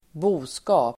Uttal: [²b'o:ska:p]